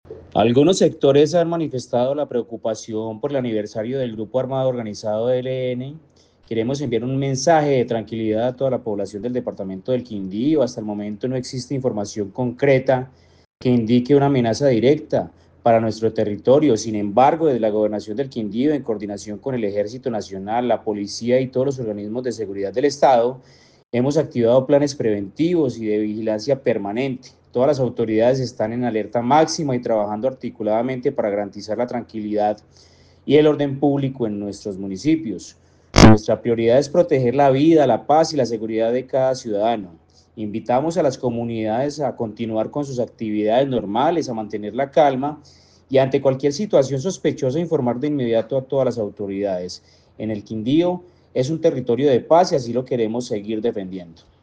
Secretario del interior encargado del Quindío